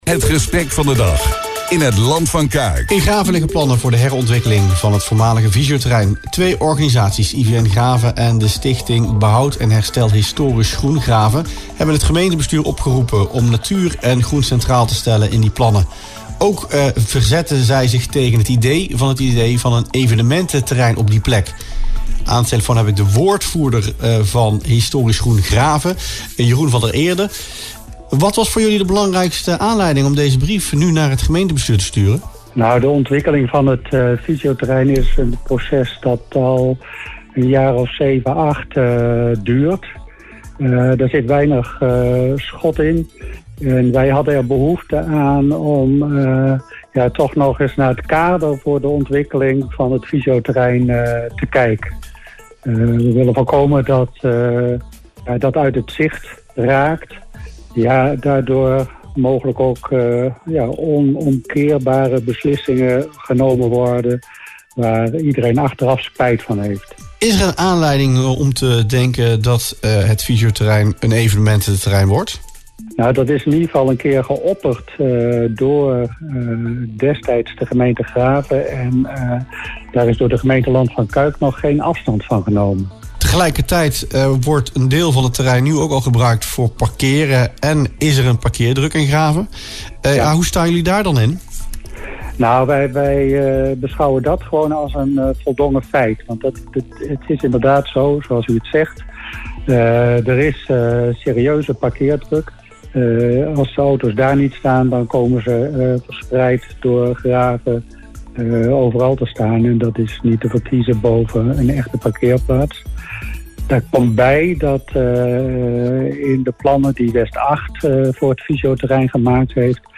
Volgens de organisaties, die hun reactie deelden in radioprogramma Rustplaats Lokkant, is meer duidelijkheid nodig over de samenhang tussen de verschillende fases van de plannen.